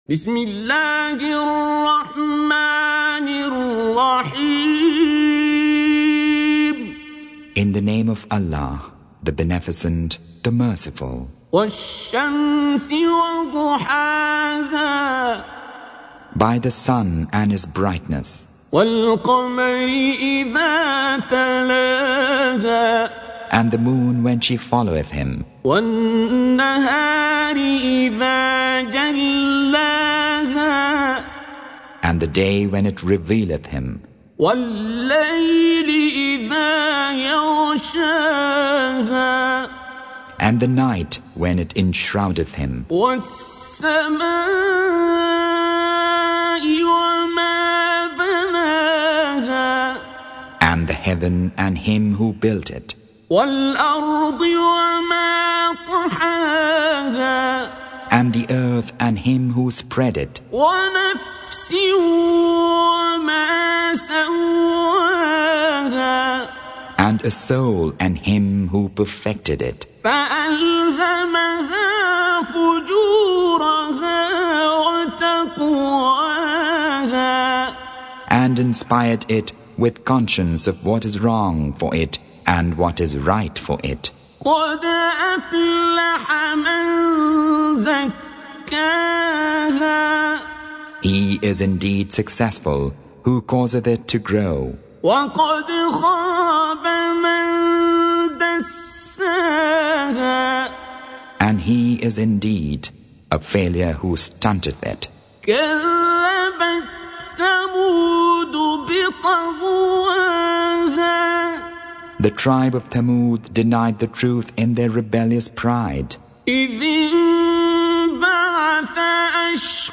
Surah Sequence تتابع السورة Download Surah حمّل السورة Reciting Mutarjamah Translation Audio for 91. Surah Ash-Shams سورة الشمس N.B *Surah Includes Al-Basmalah Reciters Sequents تتابع التلاوات Reciters Repeats تكرار التلاوات